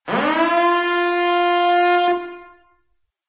Звук протяжный сигнал "тревога".